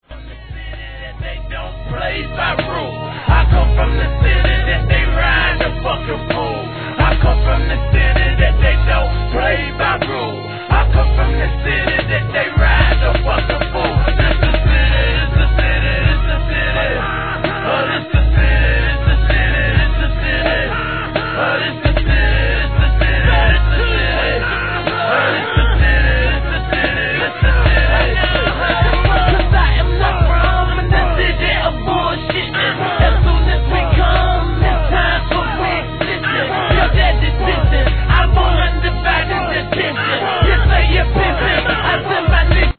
HIP HOP/R&B
ギターLOOPに男らしさ満点のSLOW バウンス!!